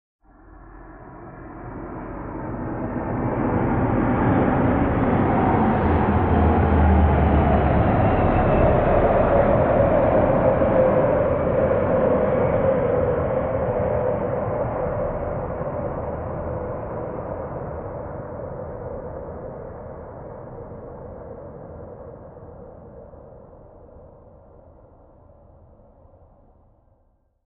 AIRCRAFT PROP TWIN TURBO: EXT: Fly by medium slow speed. Large spatial ambiance.